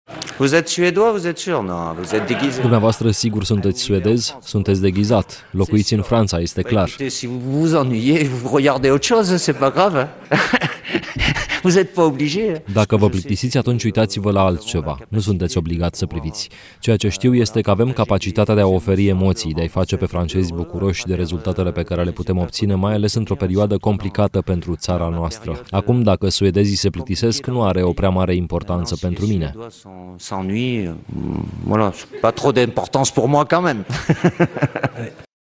Didier Deschamps, selecţionerul Franței: „Dacă suedezii se plictisesc, nu are o prea mare importanţă pentru mine”